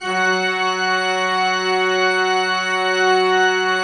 Index of /90_sSampleCDs/Propeller Island - Cathedral Organ/Partition F/MAN.V.WERK M